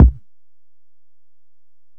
Kick (12).wav